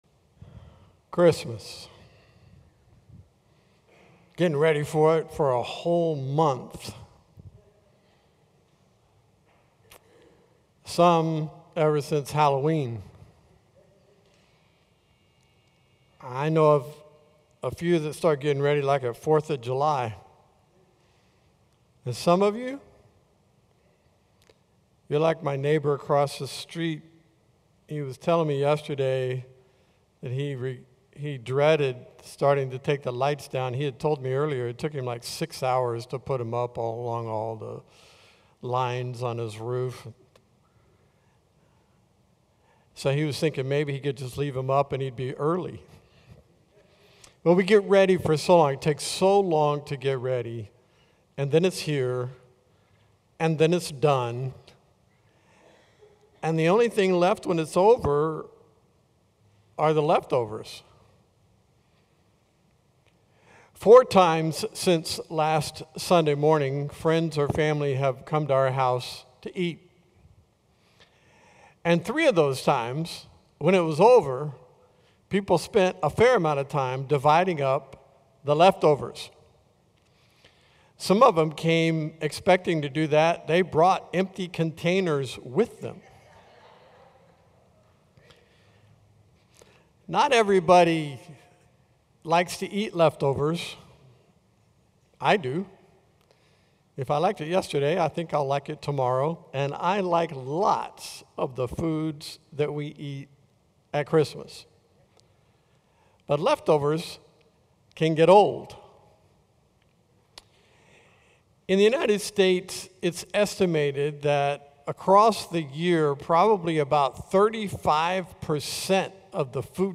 Sermons – Calvary El Calvario